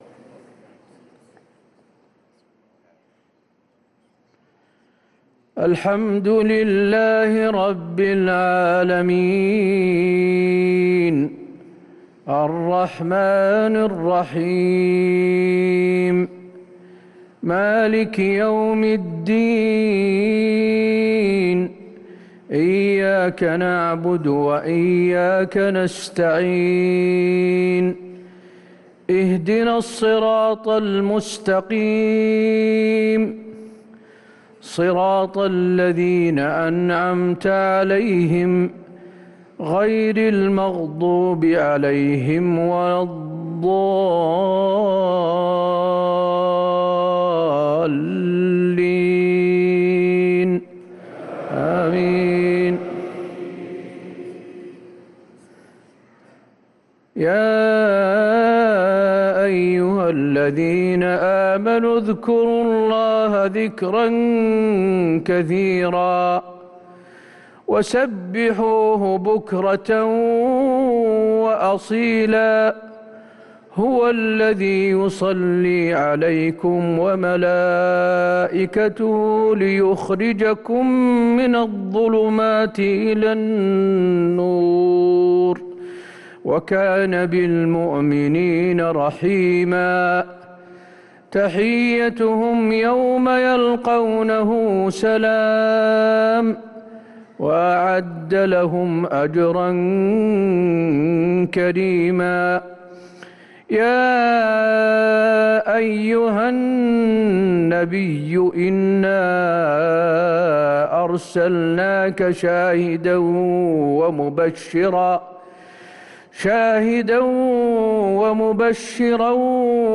صلاة العشاء للقارئ حسين آل الشيخ 7 ربيع الأول 1445 هـ
تِلَاوَات الْحَرَمَيْن .